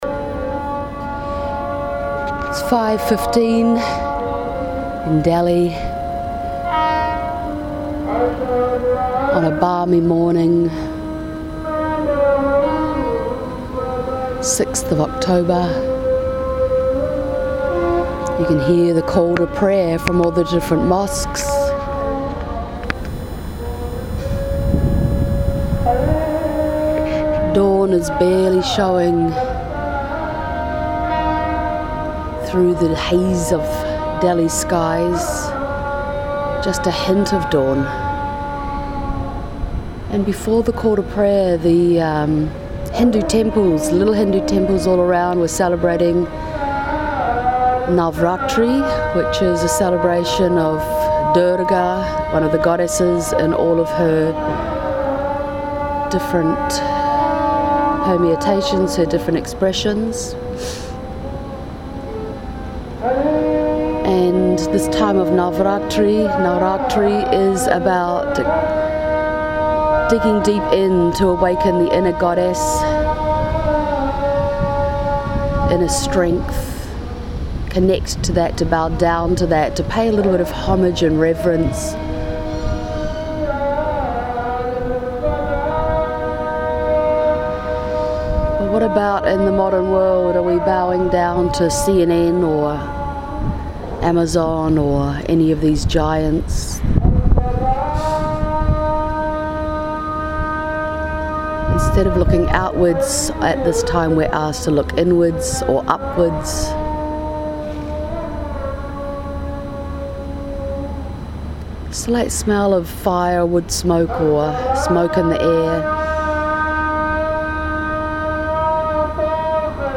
Calls to prayer woke me this morning at 5 am. Broadcasts from minarets echoed across the background noise of the waking city. This podcast captures the sound of that ‘ungodly’ godly sound.